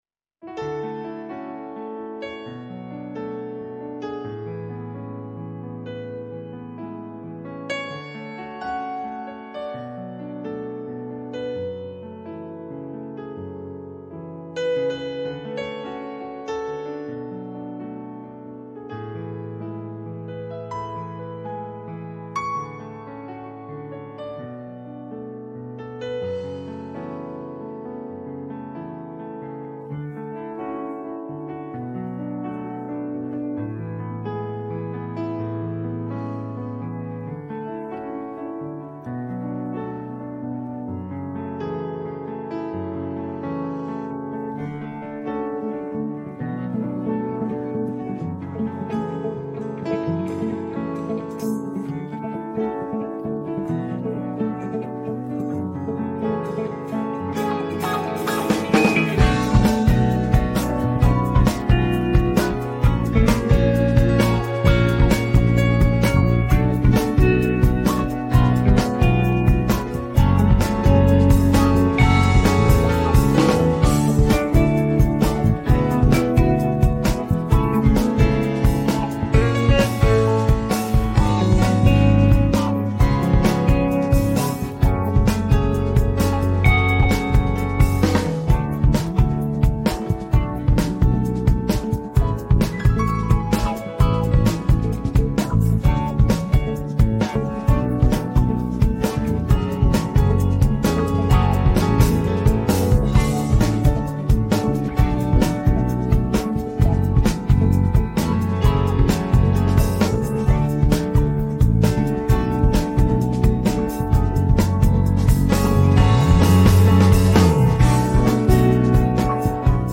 Українські хіти караоке